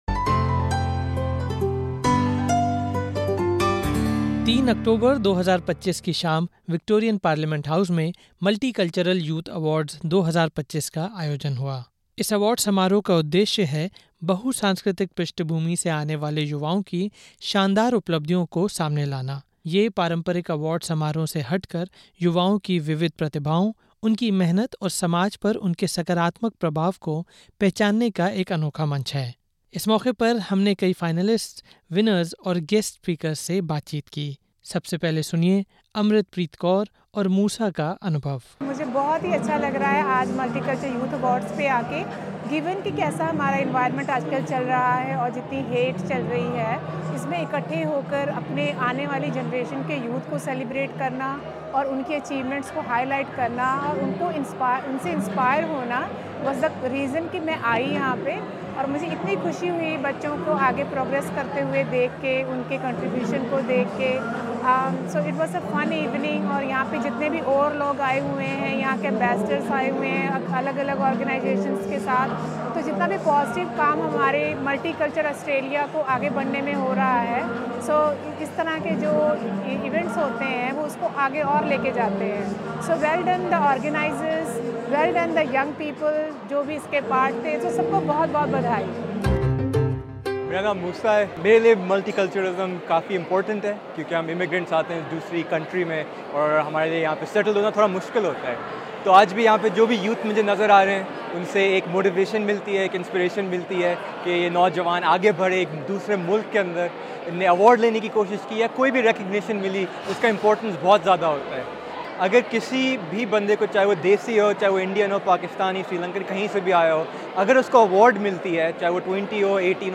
In this podcast, we bring you coverage of the Multicultural Youth Awards 2025 at the Victorian Parliament, featuring voices of finalists, winners, and guest speakers. Hear how these celebrations inspire young people and honour the contributions of immigrants, highlighting the importance of diversity in Australia amid growing anti-immigration sentiment.